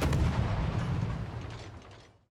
WULA_Basttleship_Shootingsound_S.wav